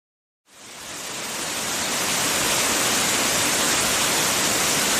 Free Animals sound effect: Dolphin Click.
Dolphin Click
488_dolphin_click.mp3